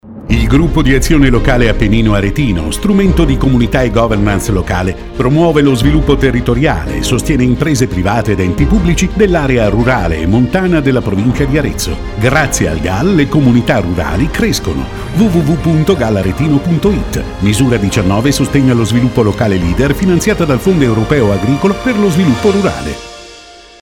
Radio Valtiberina spot n. 1